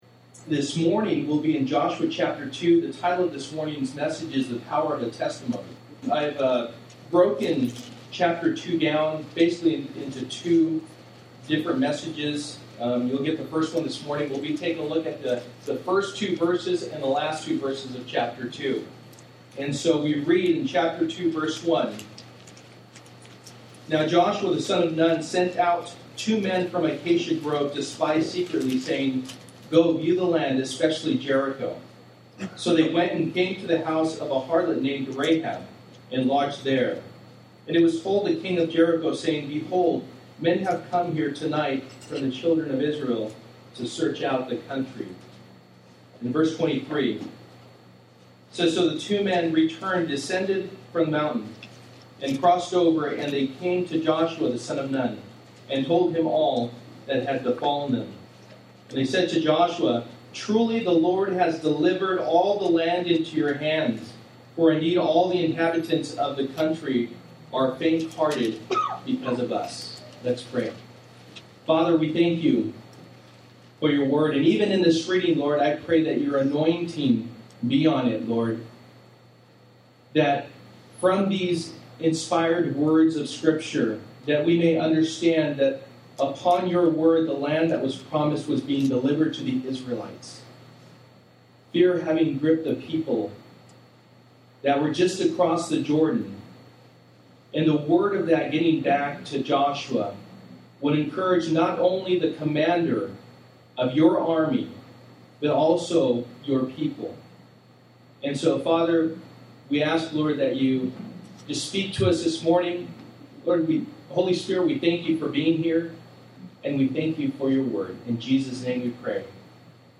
Joshua 2:23-24 Service: Sunday Morning %todo_render% « It’s Always Too Soon to Quit!